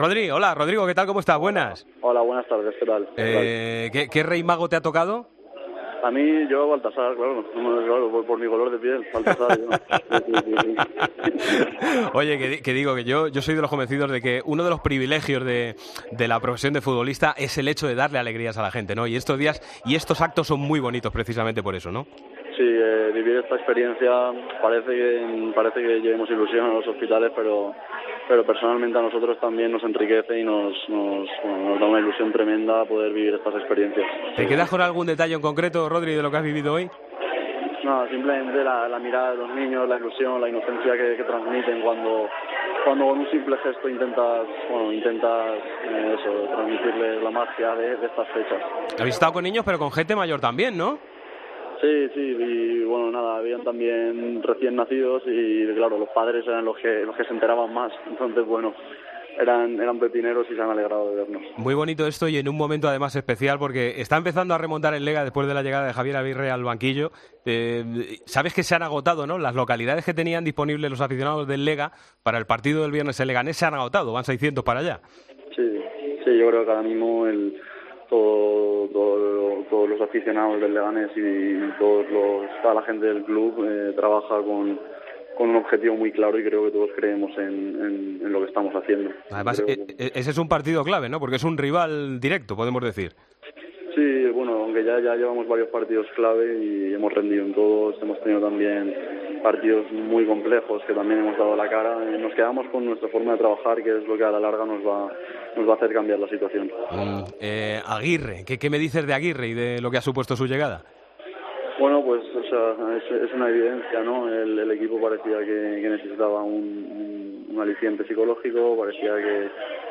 Hablamos con el jugador del Leganés sobre las opciones del equipo de lograr la permanencia en LaLiga Santander.